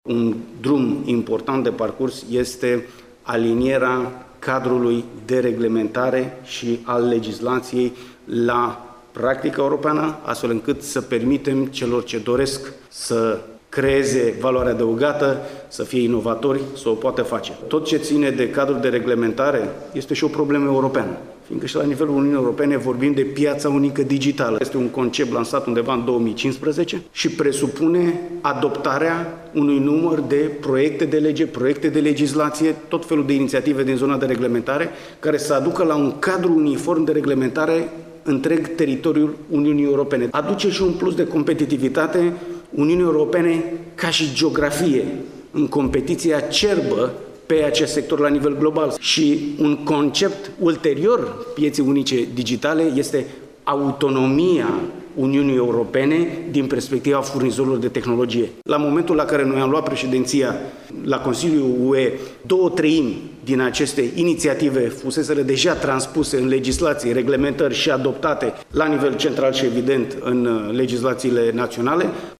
(AUDIO) Ministrul Comunicaţiilor a vorbit astăzi, la Iaşi, despre importanţa pieţei unice digitale
El participă la o conferinţă pe teme de e-guvernare unde sunt prezenţi mai mulţi specialişti europeni în domeniu.